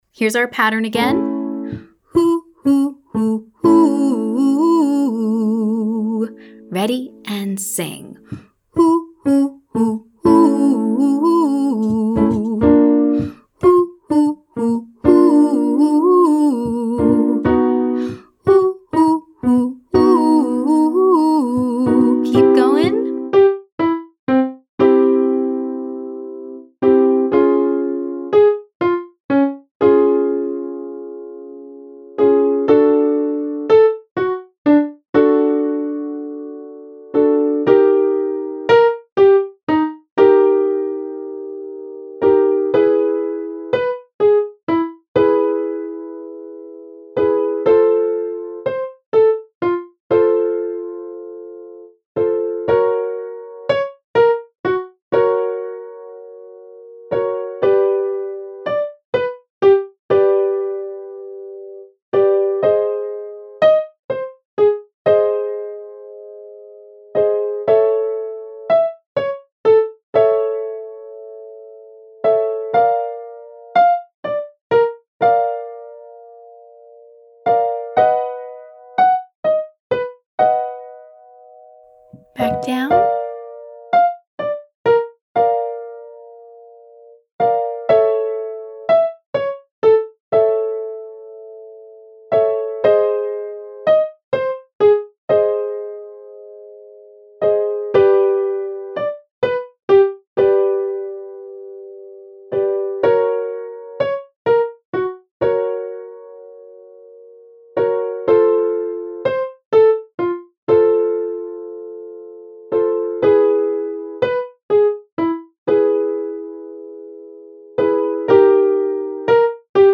There are only 3 distinct pitches in this exercise.
Vocal Agility Lesson 3C